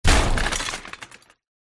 На этой странице собраны реалистичные звуки разрушения зданий: обвалы, взрывы, треск конструкций.
Сильный удар об стену эффект